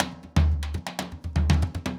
Surdo 1_Candombe 120_1.wav